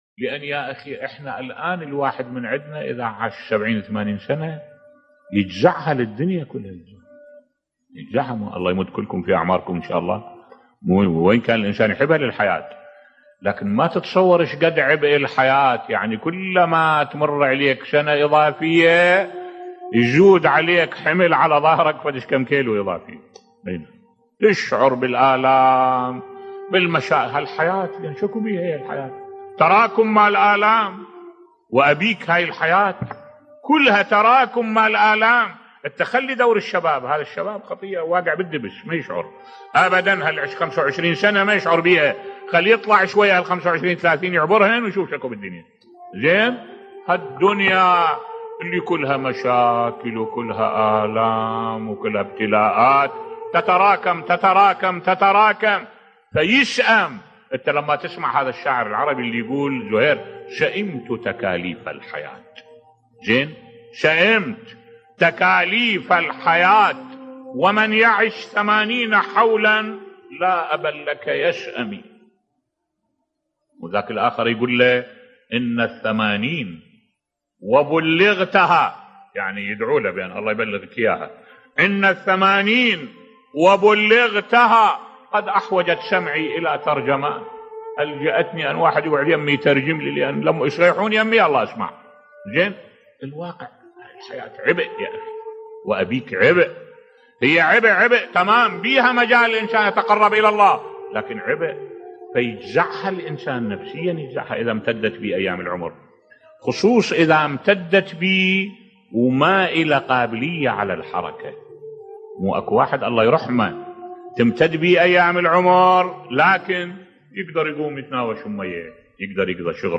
ملف صوتی كلما طالت الأعمار زادت الأعباء بصوت الشيخ الدكتور أحمد الوائلي